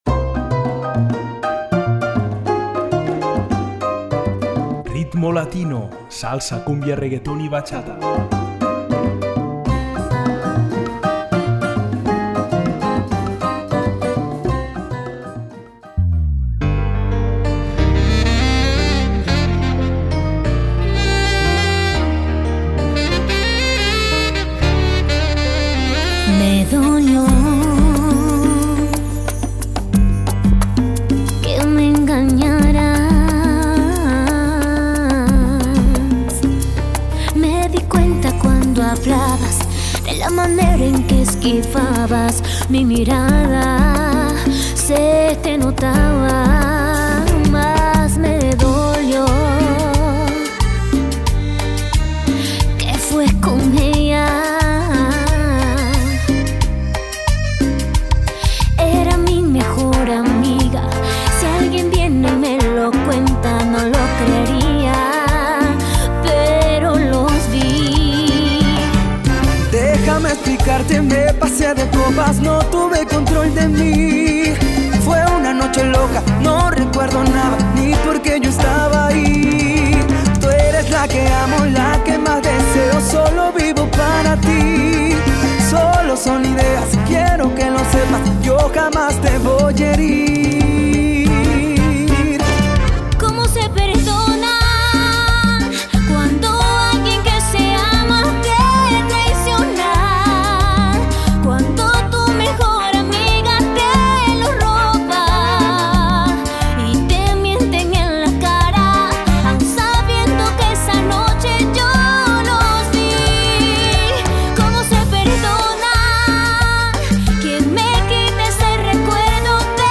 Salsa, cúmbia, reggaeton i batxata